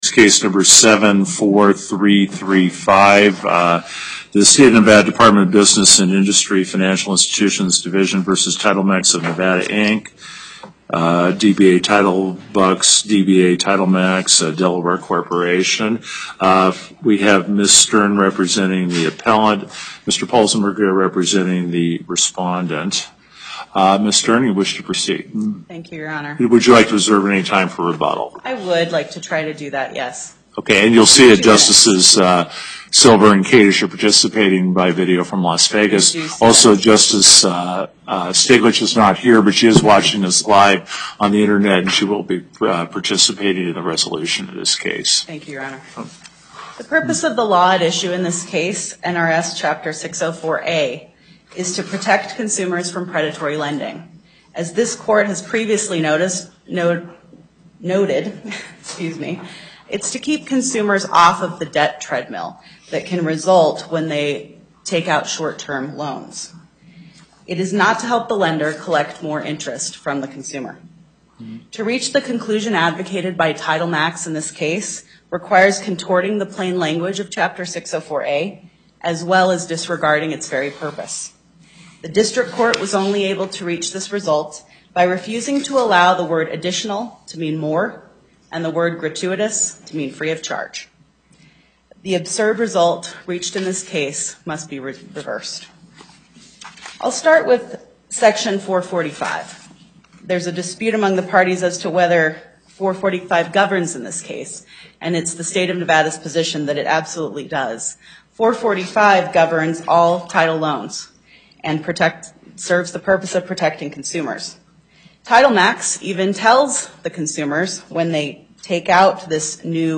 Location: Carson City Before the En Banc Court, Chief Justice Gibbons Presiding